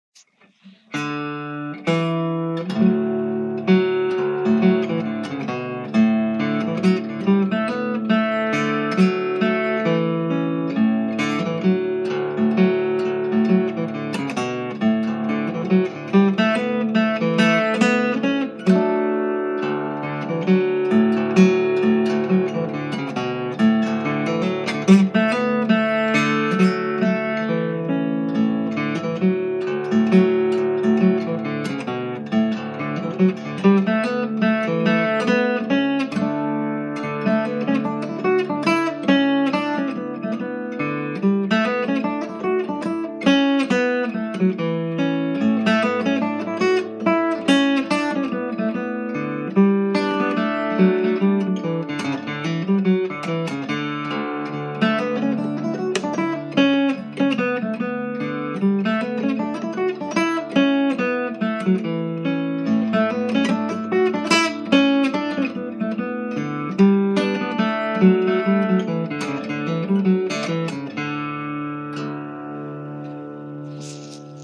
Key: D
Form: Reel